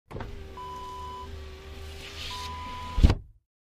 Car Power Window Close Sound Effect Wav
Description: Car power window closed
A beep sound is embedded in the audio preview file but it is not present in the high resolution downloadable wav file.
car-window-close-preview-1.mp3